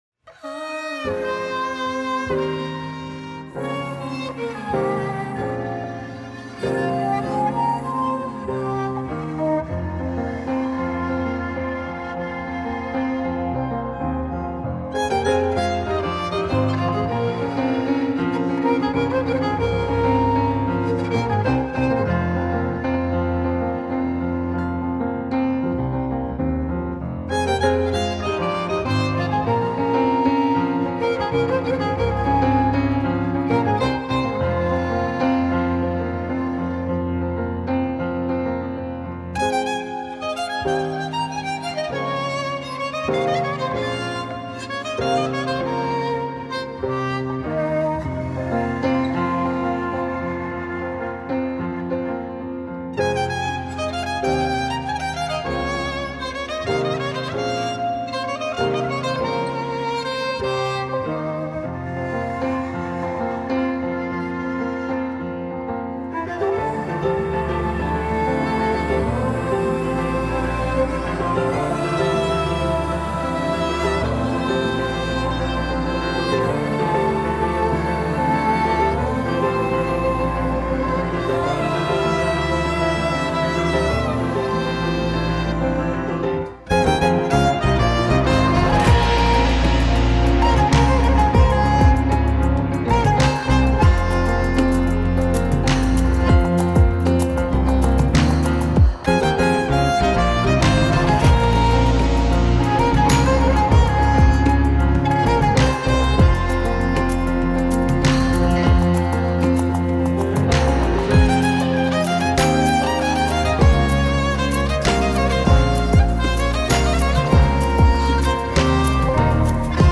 موسیقی بی کلام بومی و محلی کمانچه